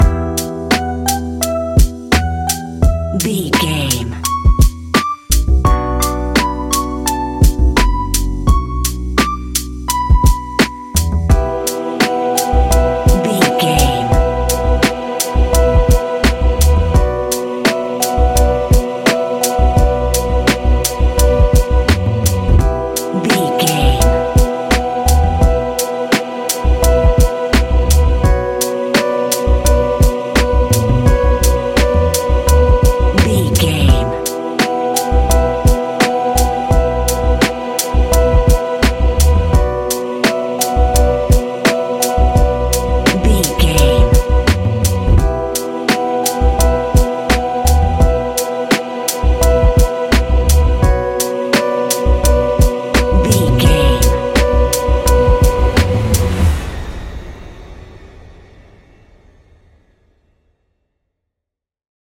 Ionian/Major
B♭
chilled
laid back
Lounge
sparse
new age
chilled electronica
ambient
atmospheric
morphing